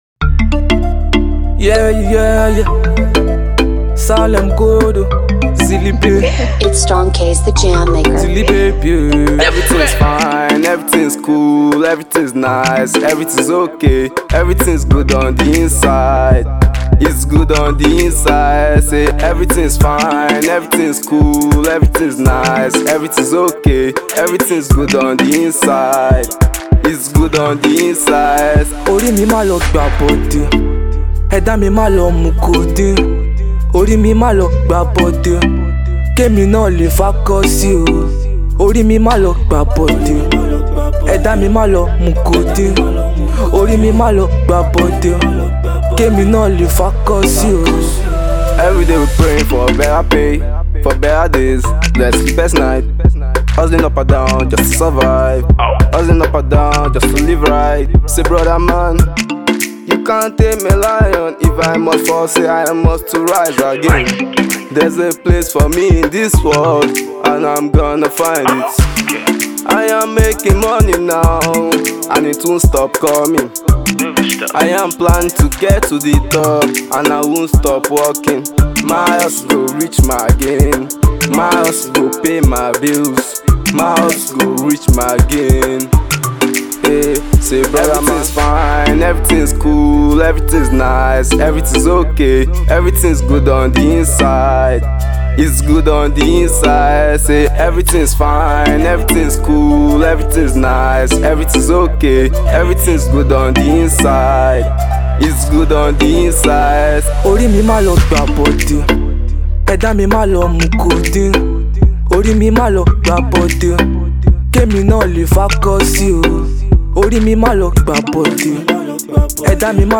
motivational sound